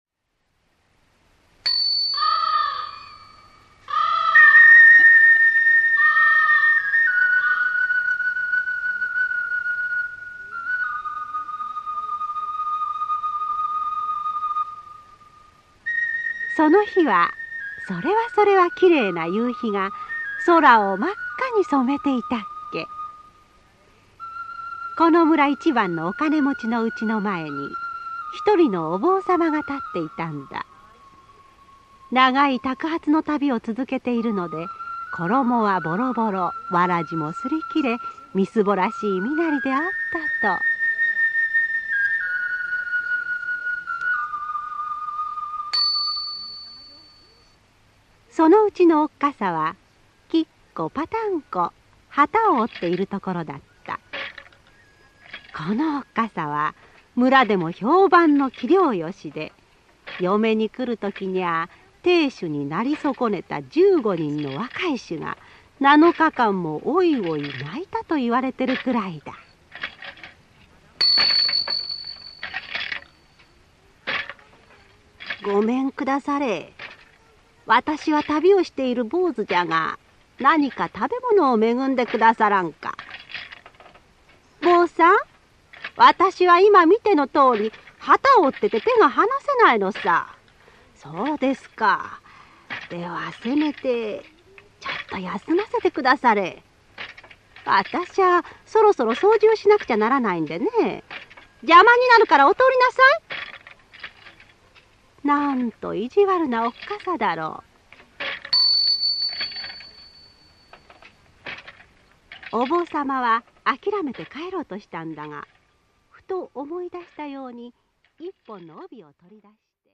[オーディオブック] 宝てぬぐい